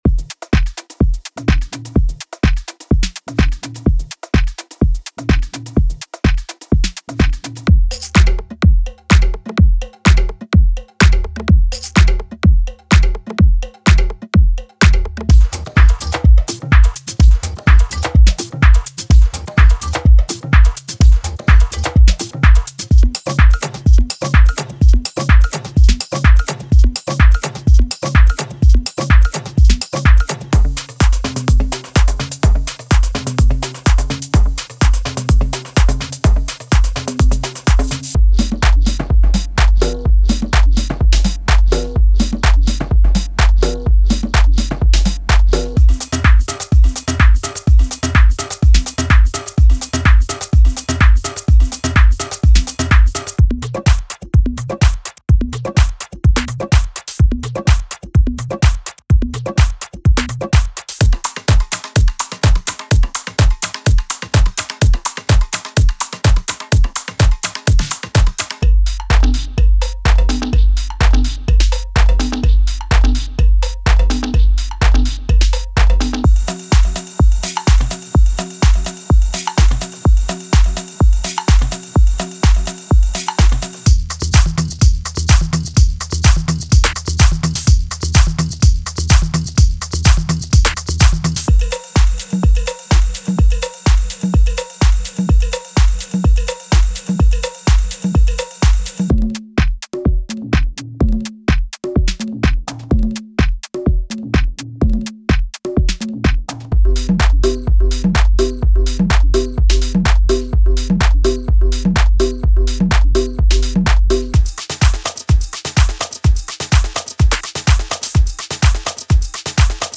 Deep House House Melodic Techno Tech House Techno Tribal
100 Percussion Loops